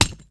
wrench_hit_stone2.wav